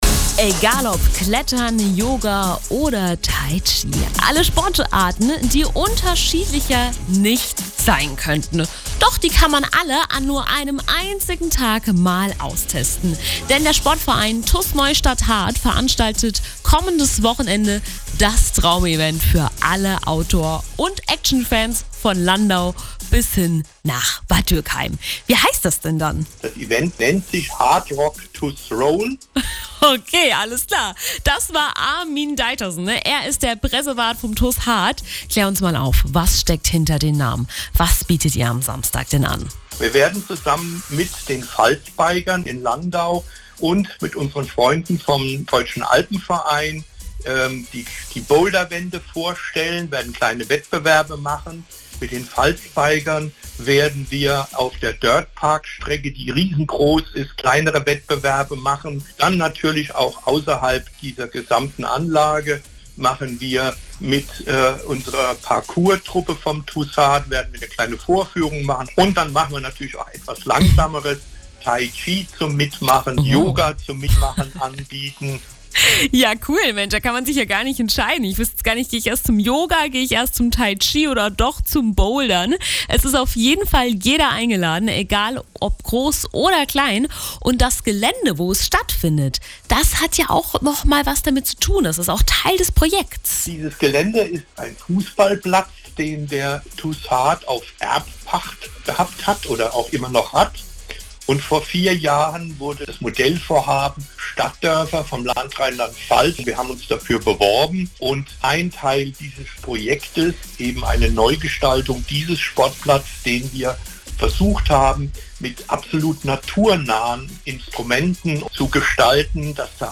. 0000:00 Interview mit Antenne Pfalz Mitschnitt Tus Haardt 00:00 00:00 00:00 Haardt Rock - TuS Roll Outdoor Action am 21.9. auf dem Sportplatz Ludwigsbrunnen .